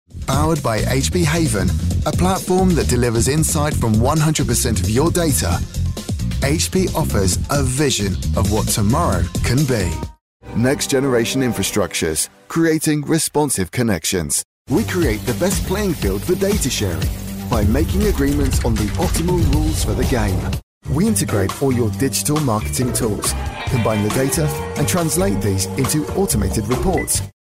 English (British)
Natural, Cool, Accessible, Distinctive, Warm
Corporate